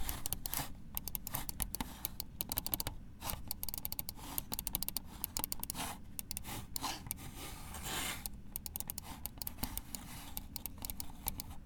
Computer Mouse_Fast
computer keyboard mouse typing sound effect free sound royalty free Memes